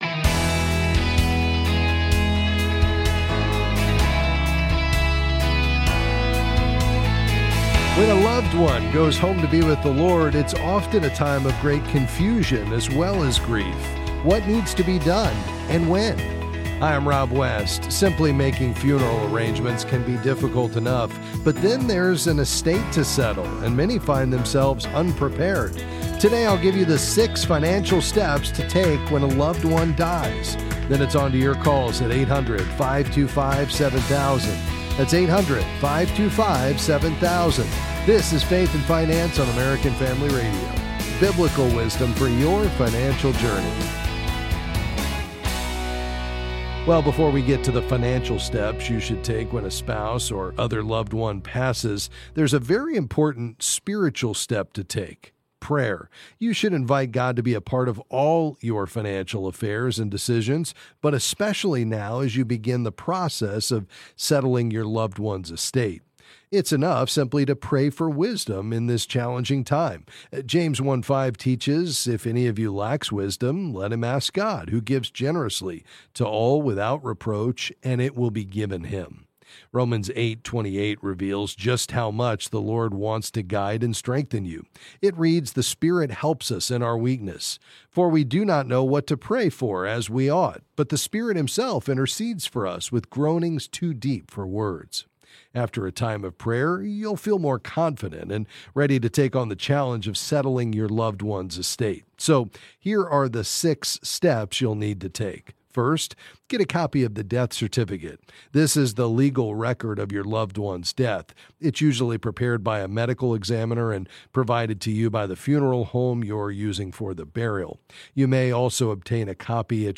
Then he’ll answer calls on various financial topics.